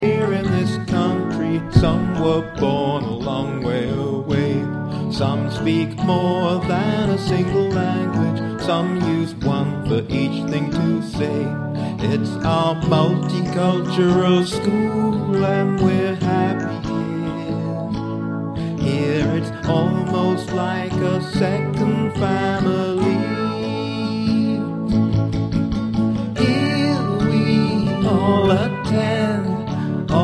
Listen to the vocal track.